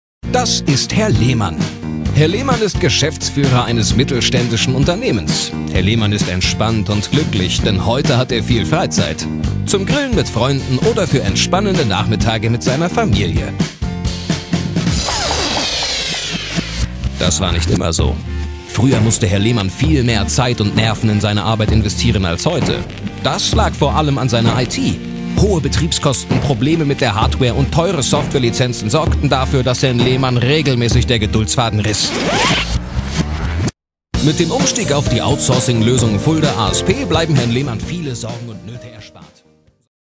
Wandelbare, freundliche und angenehme Männerstimme mittleren Alters.
Sprechprobe: eLearning (Muttersprache):